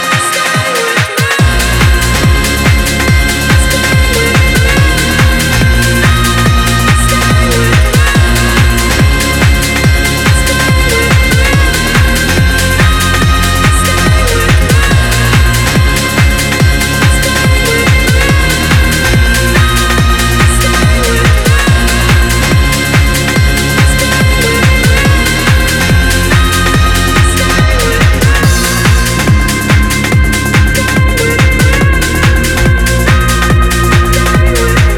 Модульные синтезаторы и глитчи трека
2025-07-10 Жанр: Электроника Длительность